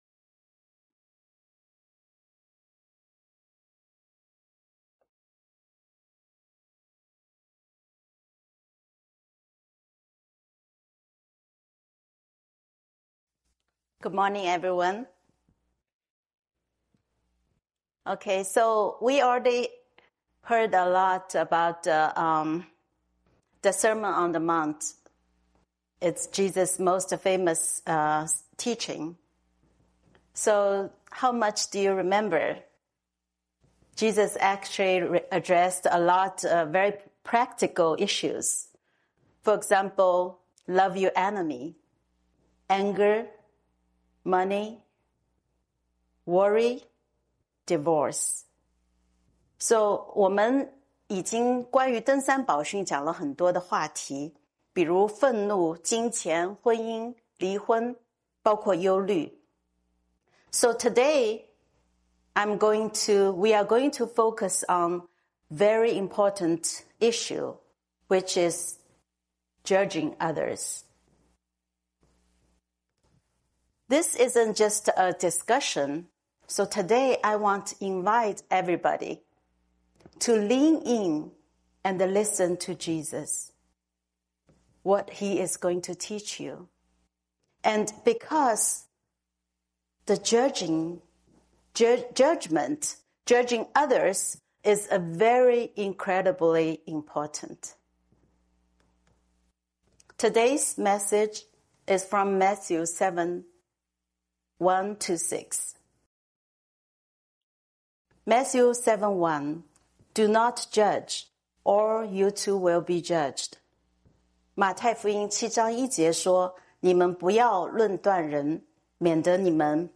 Sermons Archive - Living Hope Fellowship